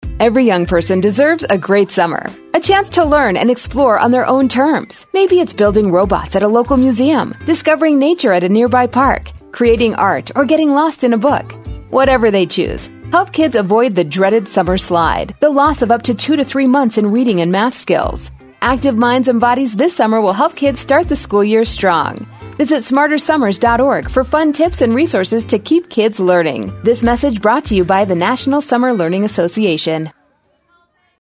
2018-Summer-Learning-PSA.mp3